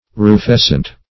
Search Result for " rufescent" : The Collaborative International Dictionary of English v.0.48: Rufescent \Ru*fes"cent\ (r[.u]*f[e^]s"sent), a. [L. rufescens, p. pr. of rufescere to become reddish, fr. rufus red: cf. F. rufescent.]
rufescent.mp3